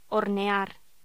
Locución: Hornear